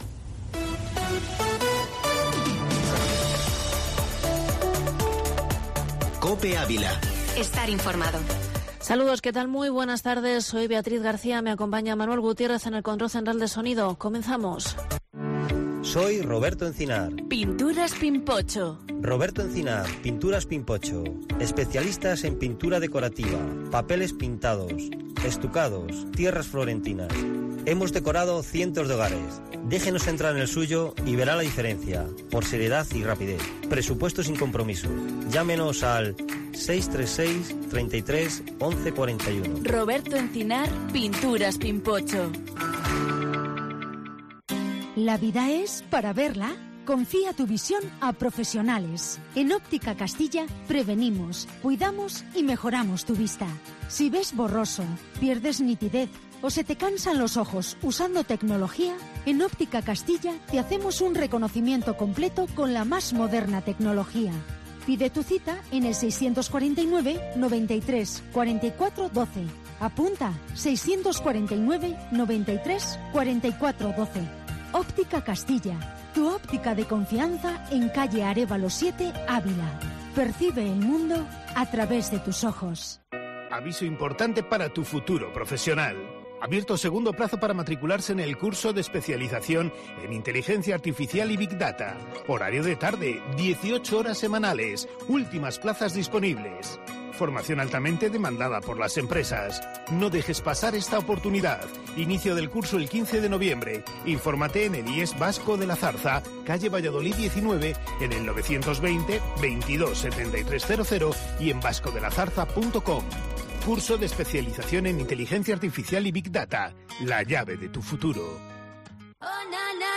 Redacción digital Madrid - Publicado el 08 nov 2021, 14:06 - Actualizado 18 mar 2023, 04:01 1 min lectura Descargar Facebook Twitter Whatsapp Telegram Enviar por email Copiar enlace Entrevista con el ex-Subdelegado del Gobierno en Ávila, Arturo Barral.